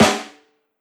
• Verby Steel Snare Drum Sample A Key 54.wav
Royality free acoustic snare sample tuned to the A note. Loudest frequency: 1693Hz
verby-steel-snare-drum-sample-a-key-54-HqC.wav